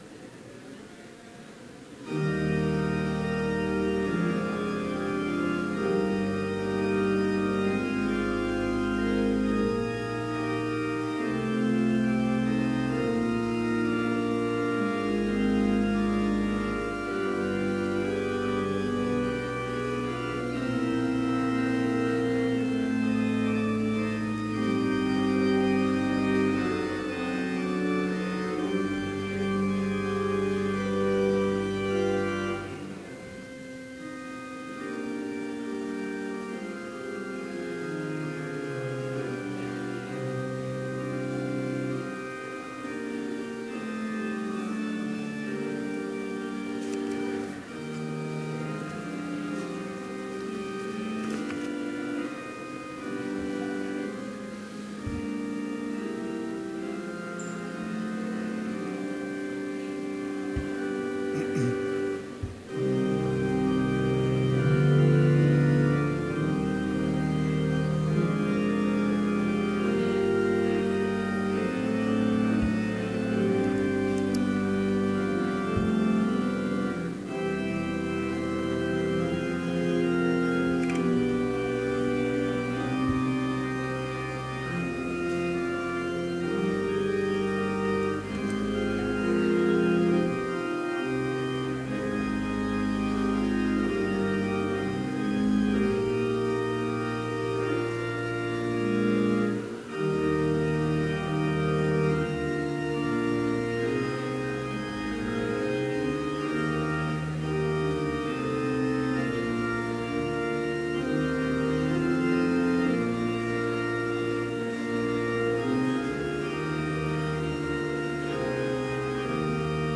Chapel worship service held on December 9, 2016, BLC Trinity Chapel, Mankato, Minnesota,
Complete service audio for Chapel - December 9, 2016